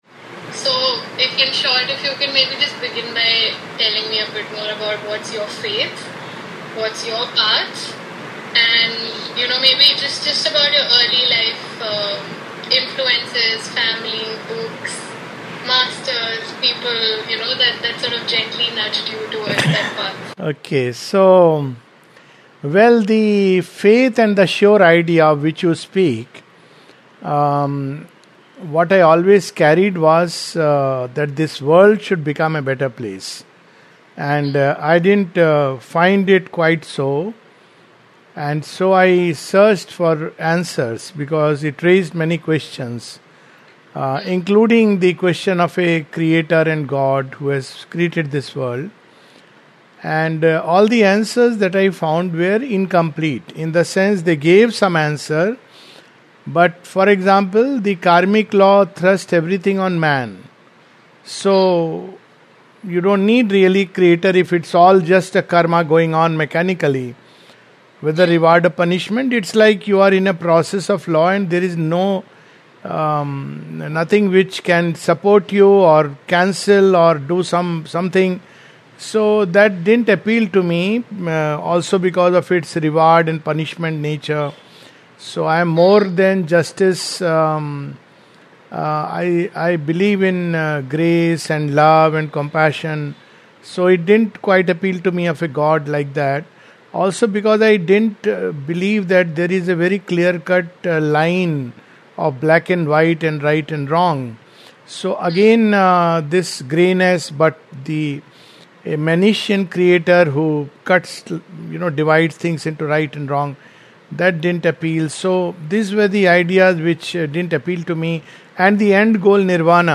An interview recorded on 23rd July 2025